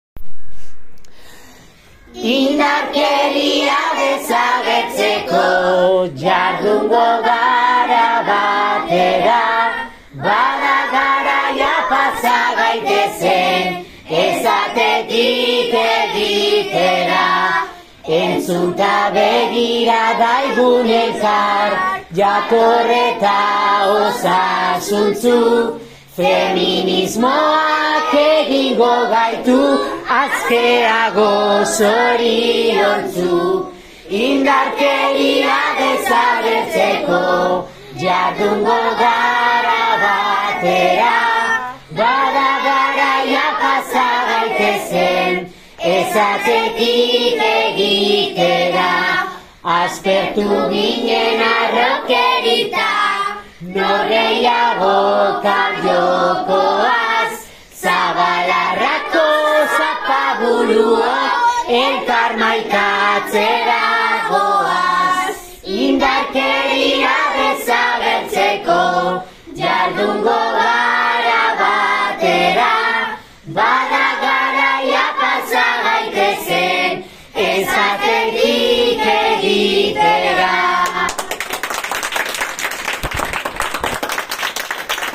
Atzo, azaroak 25, Emakumeen kontrako indarkeriaren aurka egindako bertsoa ozen entzun zen.